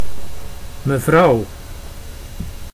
Ääntäminen
IPA: /məˈvrɑu̯/